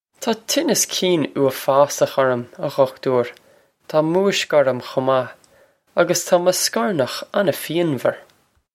Pronunciation for how to say
Taw cheen-as keen oo-a-faws-okh orr-um, ah ghukh-too-ir. Taw moo-ishk orr-um khoh mah, a-gus taw muh scor-nokh on-fee-on-var.